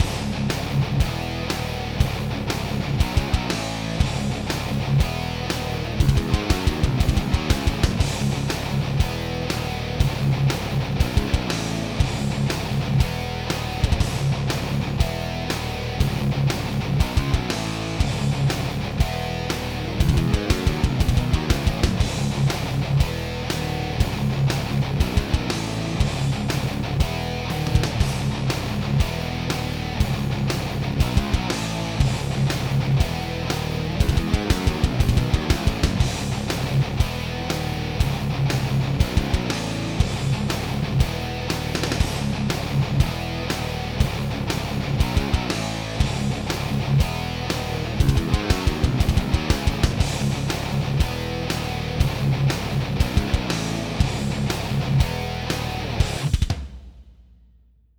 I did a quick pickup test of each using a recto setting in the POD.
The clip goes through one of my newer riffs four times, first the Painkiller, then the Rebel Yell, then the Cold Sweat, and finally back to the Painkiller . The playing isn't very clean, but it's good enough to get an idea for each pickup. The bass is from a keyboard, and the drums are DFHS2.0, everything was recorded 16 bit, 44khz.
pickuptest.wav